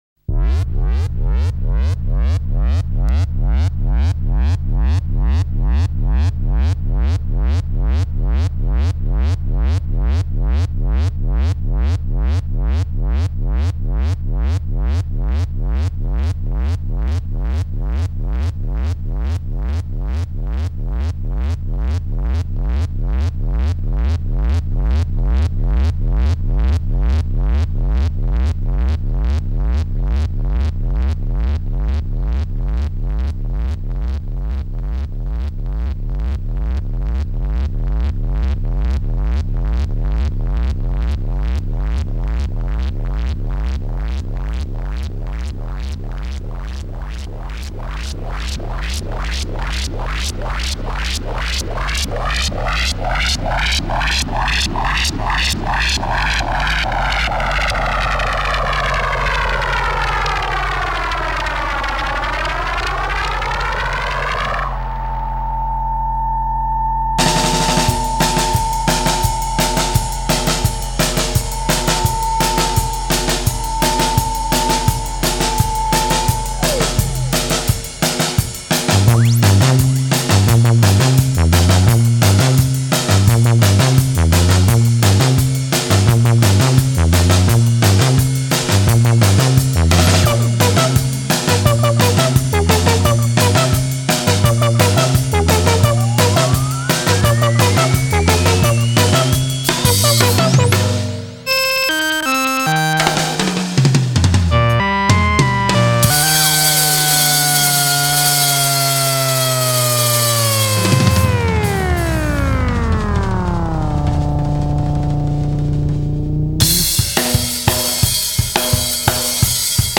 -the use of moog and other keyboards
-the interaction between electronics and guitars
-the musical build over repetition